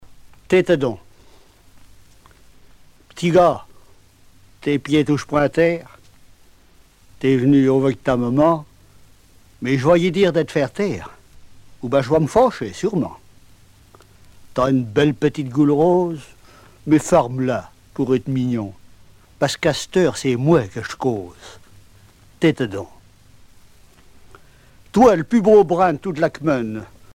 Genre poésie
Catégorie Récit